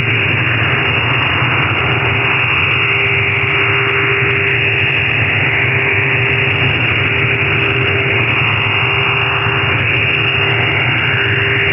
Orthogonal frequency division multiplexing (OFDM) is a transmission technique that consists of the multiplexing of a set of carrier waves of different frequencies, where each one carries information, which is modulated in QAM. or in PSK.
Modulation: OFDM
Bandwidth: 2.4 kHz
Baud rate: 39 x 44.44 Bd